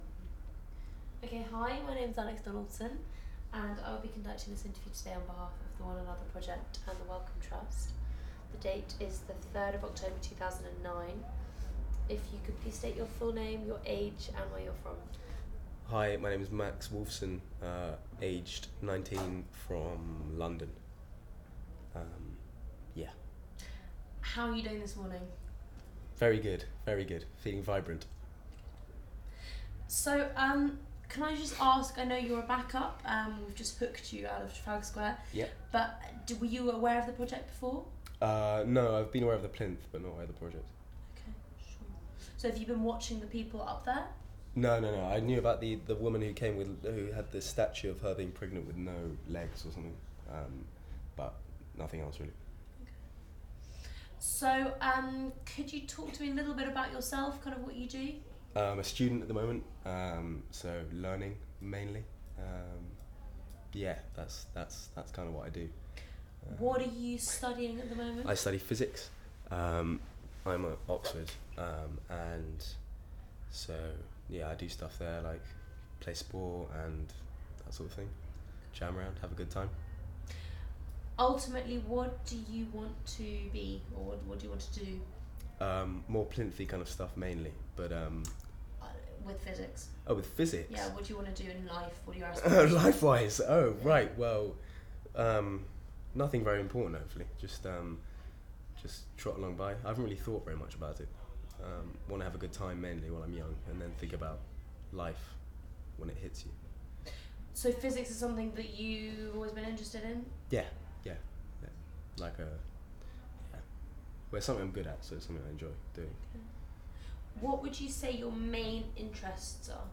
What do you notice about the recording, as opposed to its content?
Audio file duration: 00:08:03 Format of original recording: wav 44.1 khz 16 bit ZOOM digital recorder.